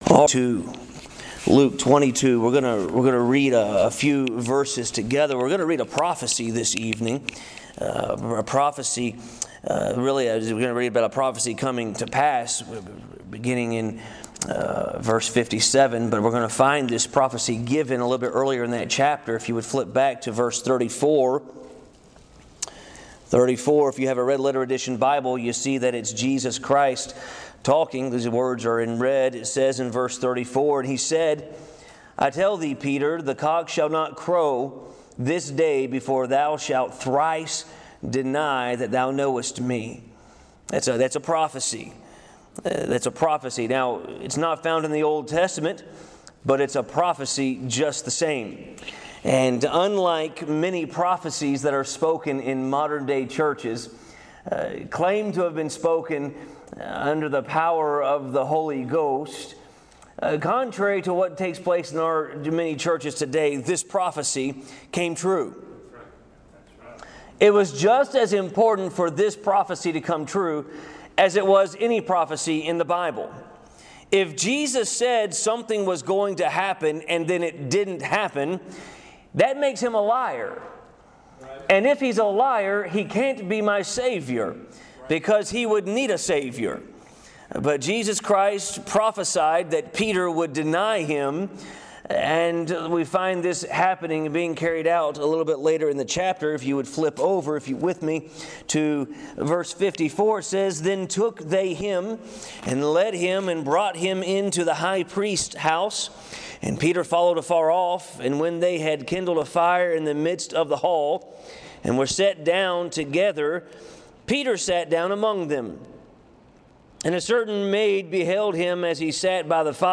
August 1, 2018 (Wednesday Evening)